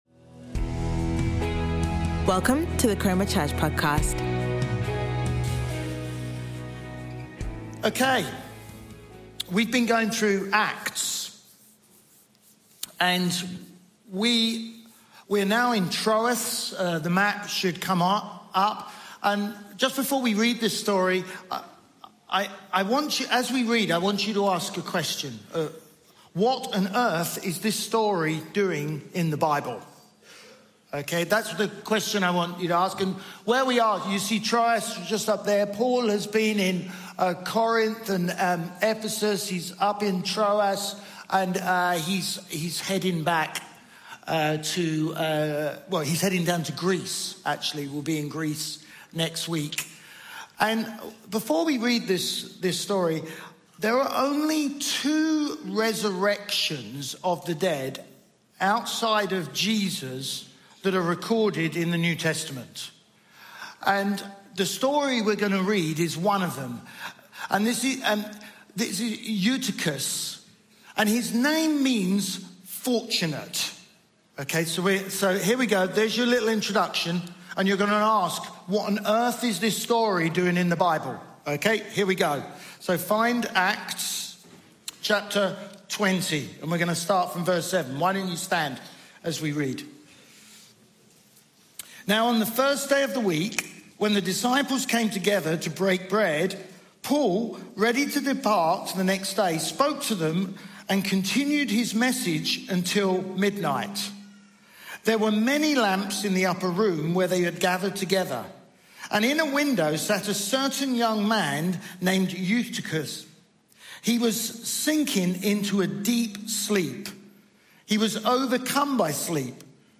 Chroma Church Live Stream
Sunday Sermon